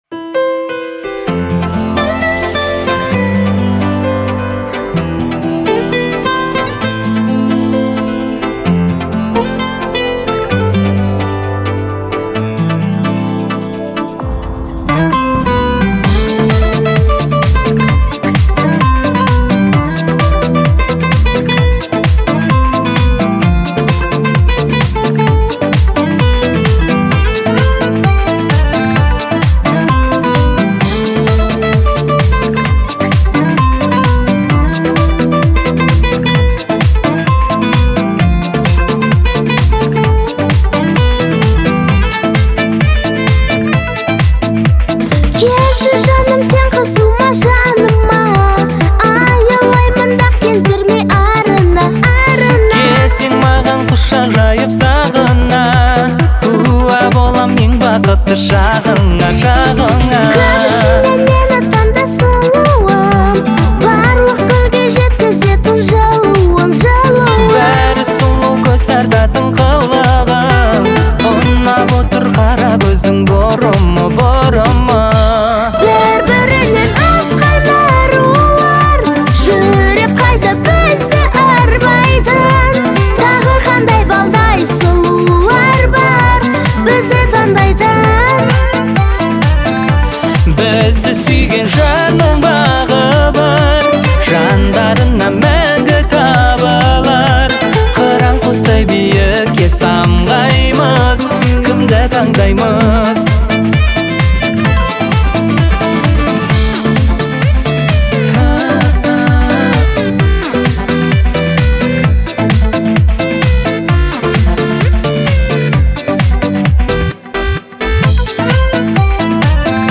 красивую и трогательную балладу в жанре поп